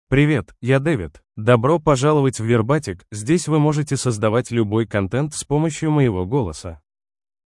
David — Male Russian AI voice
David is a male AI voice for Russian (Russia).
Voice sample
Male
David delivers clear pronunciation with authentic Russia Russian intonation, making your content sound professionally produced.